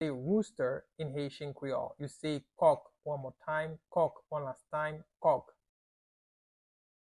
Pronunciation:
Rooster-in-Haitian-Creole-Kok-pronunciation-by-a-Haitian-teacher.mp3